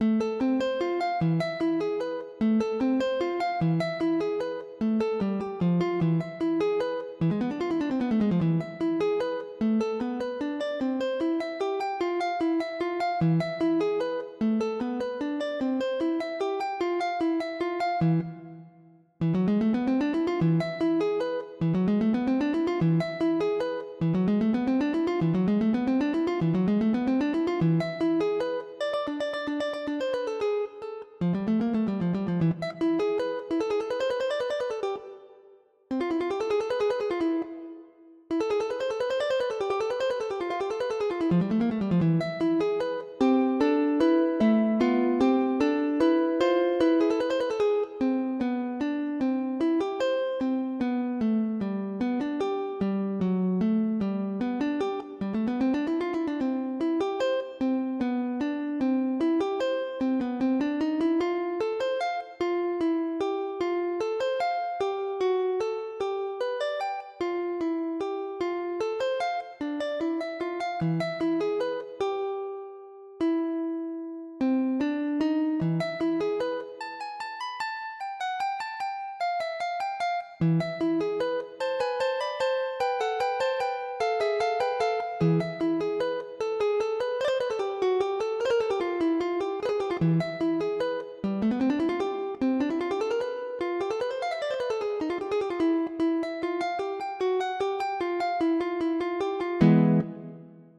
Leçon de guitare
Cours de Flamenco
Etude n°1 : pour oud (solea) | Niveau débutant
J’ai écrit cette solea pour les jeunes marocains du conservatoire de Marakkech (Maroc) en avril 99, pour oud ou guitare.